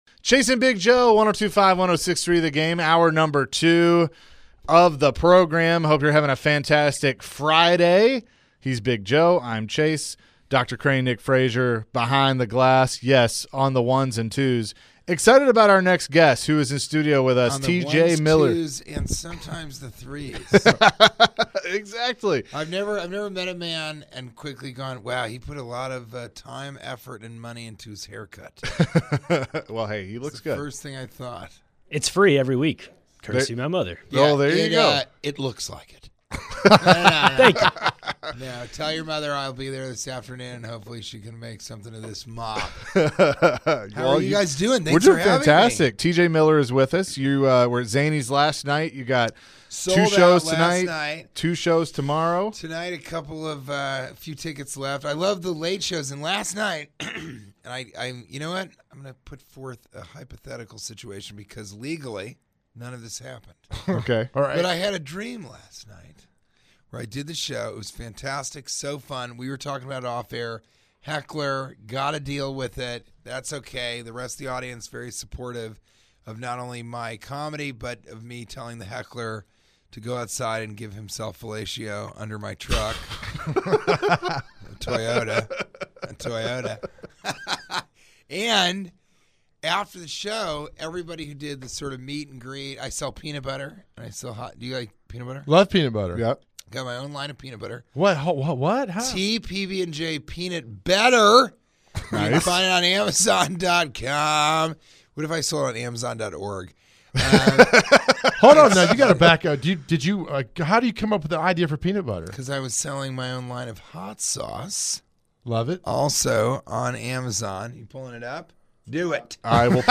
were joined by comedian TJ Miller in the studio.